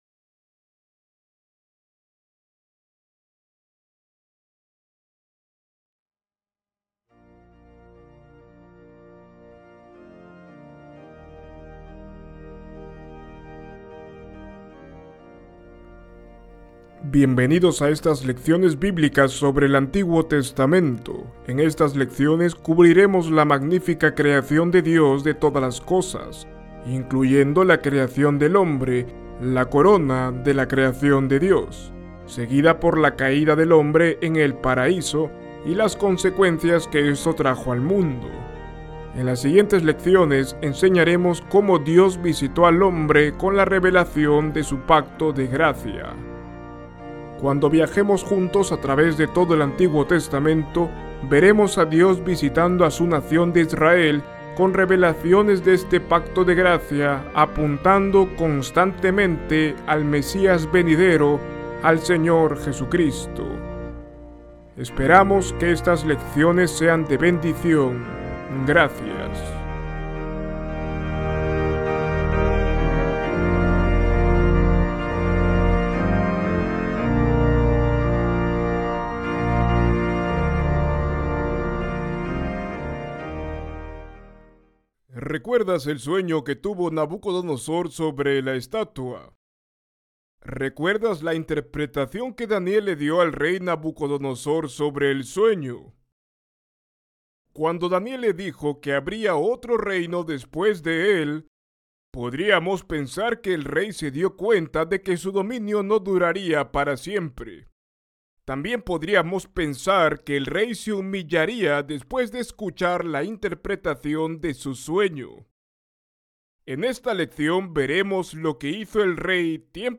En esta lección veremos todos los detalles de lo que pasó, y puede que, tal vez, te sorprendas. Ver video Descargar video MP4 Escuchar lección Descargar audio en mp3 Ver transcripción en PDF Descargar transcripción en PDF Guia de Estudio